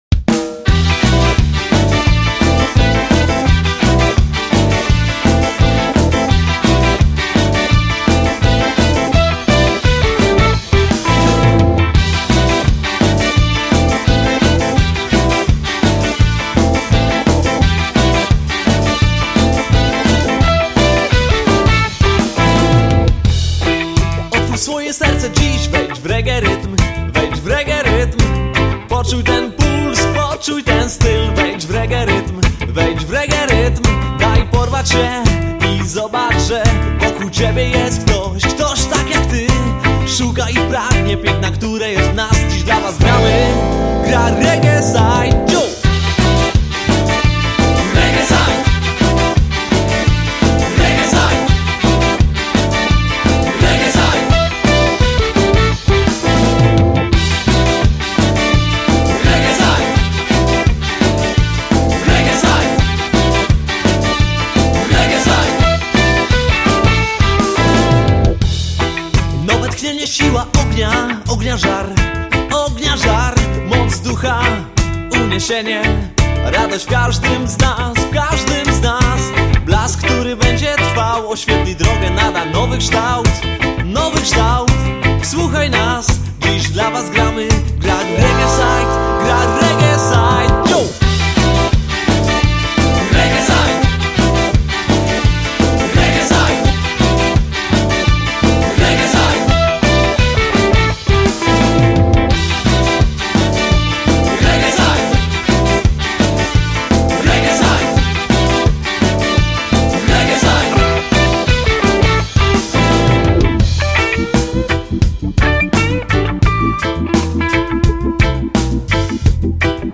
klawisze
bębny
trąbka
sax
gitara